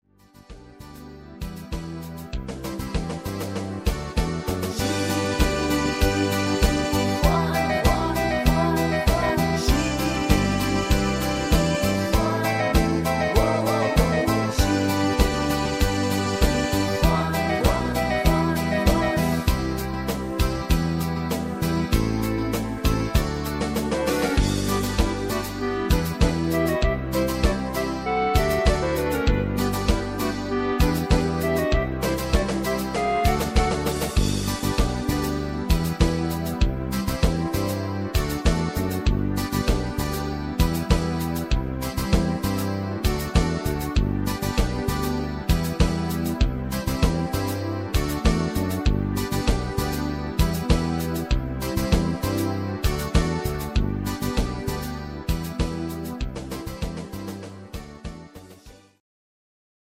Rhythmus  Reggae
Art  Deutsch, Schlager 80er